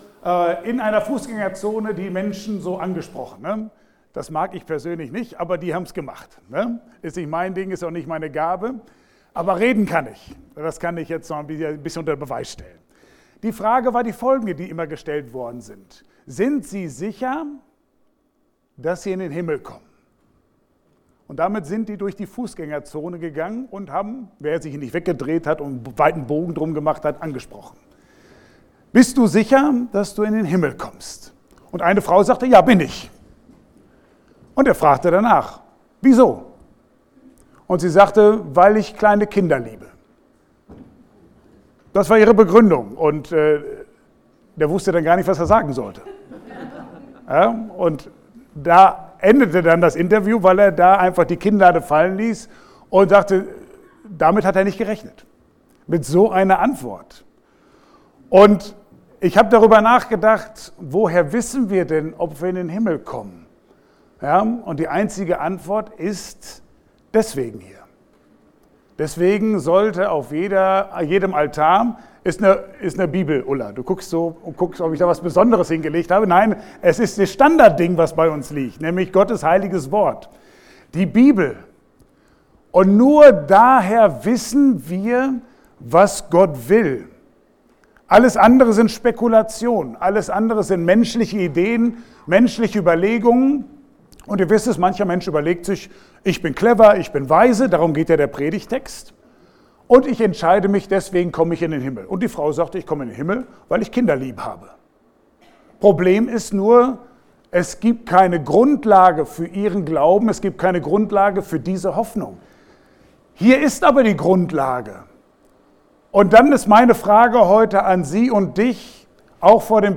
Passage: Prediger 7, 15-18 Dienstart: Gottesdienst « Kein Sturm